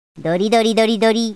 neco arc dori Meme Effect sound effects free download